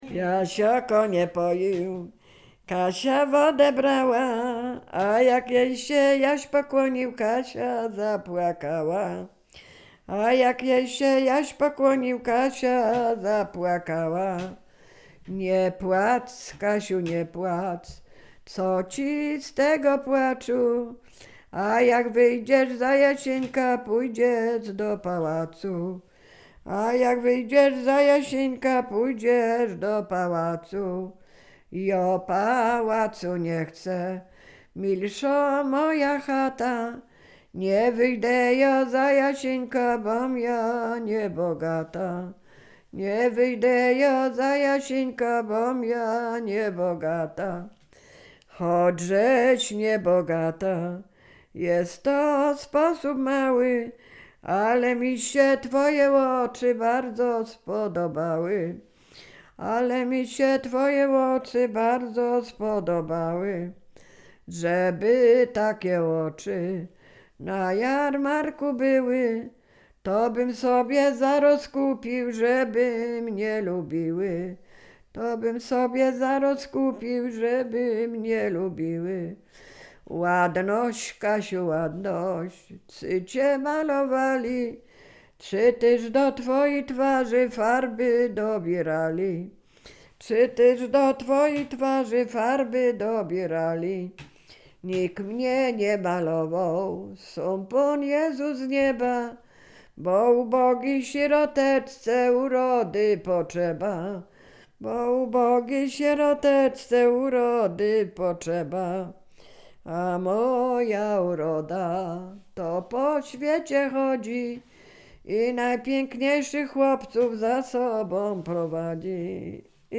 Sieradzkie
wojewódzctwo łódzkie, powiat sieradzki, gmina Brzeźnio, wieś Kliczków Mały
Liryczna
liryczne miłosne